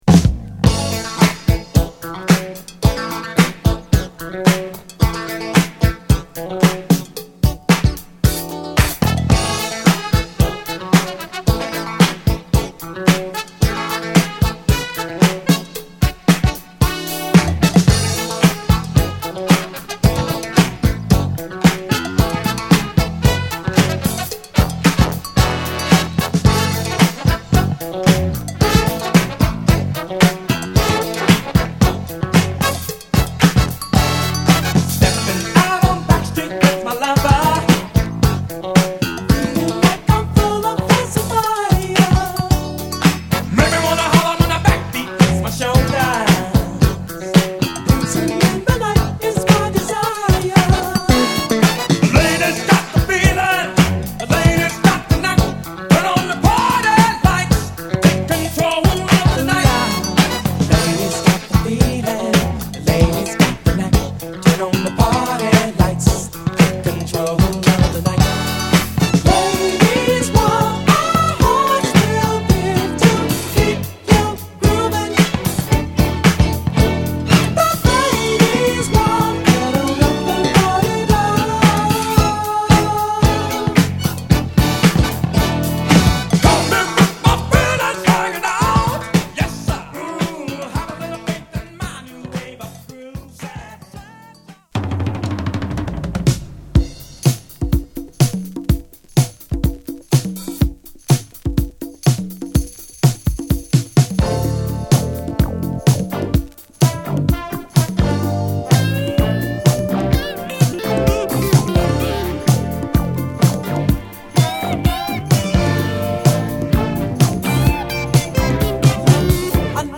カッコ良いドラムが引っ張るブレイク感あるイントロから、タメの効いたグルーヴでファンキーに展開するブギーディスコ！